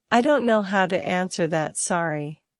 Play, download and share siri – i dont know how to answer that sorry original sound button!!!!
siri-i-dont-know-how-to-answer-that-sorry.mp3